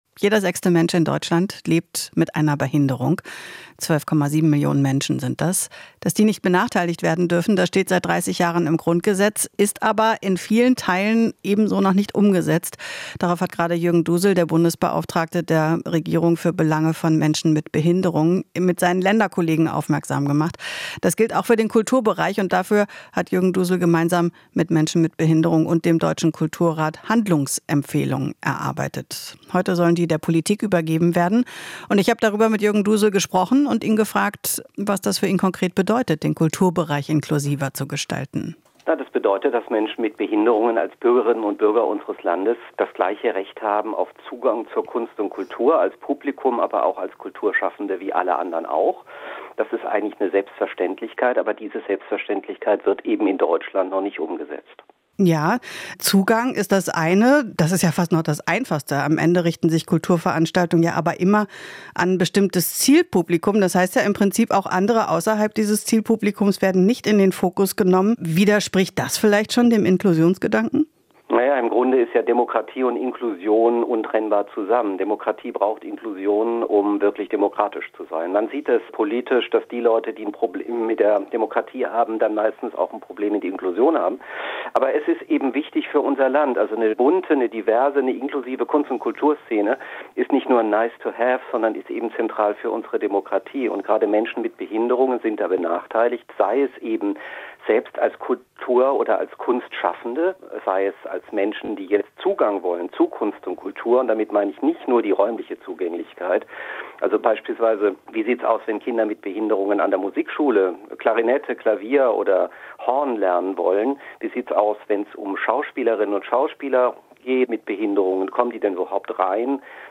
Interview - Behindertenbeauftragter: Staat muss Versprechen in der Kultur halten
Noch immer gibt es große Hürden für Menschen mit Behinderung, sich als Künstler zu betätigen, sagt Jürgen Dusel, Beauftragter der Bundesregierung für die Belange von Menschen mit Behinderung.